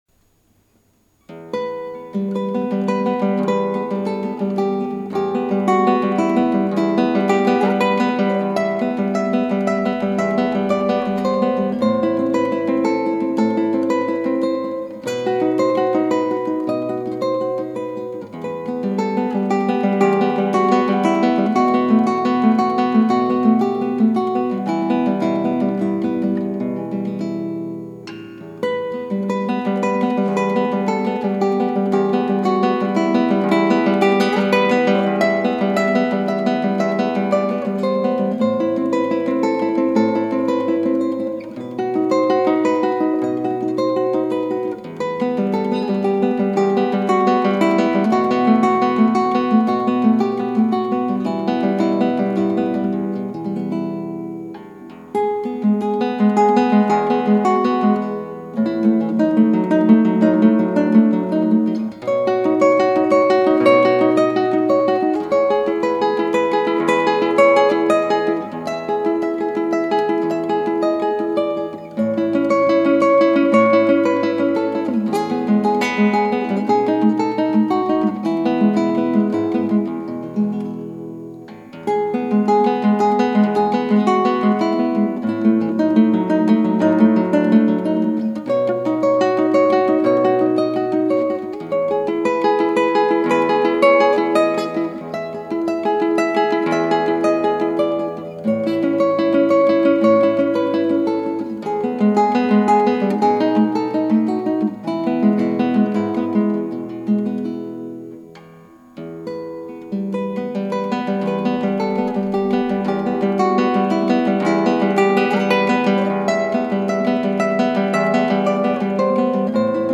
クラシックギター　ストリーミング　コンサート
１．この曲は美しくシンプル
う〜ん、２テイク取ったのですが、どっちも一長一短でした。
この曲・・・こんな悲しい曲だっけ・・・。録音のとき別にブルーになってたわけではないのに、やけに悲しい曲に仕上がっちゃいました。